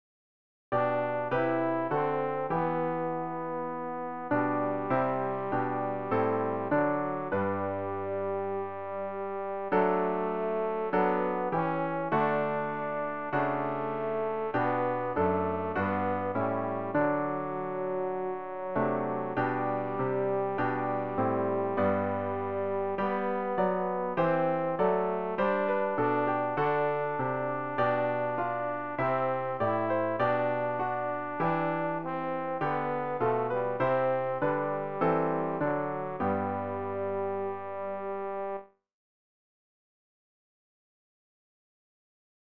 Übehilfen für das Erlernen von Liedern
rg-865-Herr-mach-uns-stark-tenor.mp3